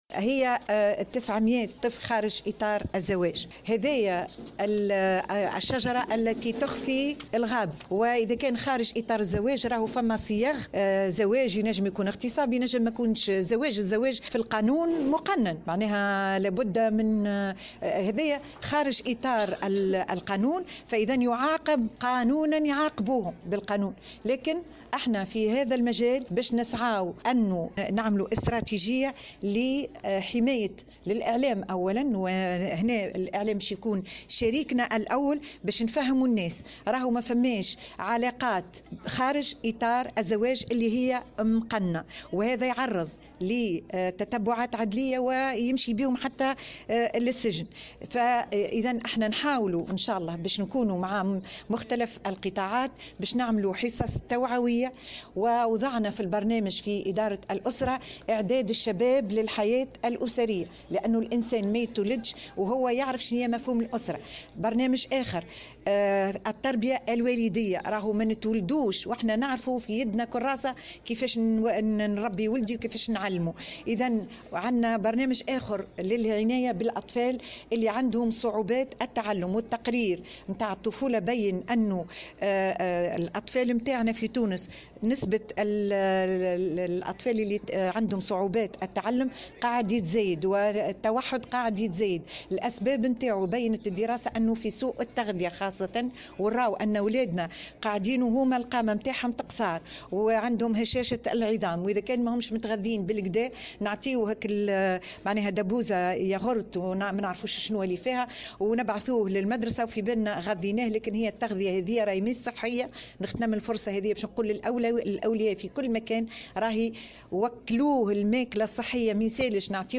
في تصريح لمراسلة الجوهرة أف أم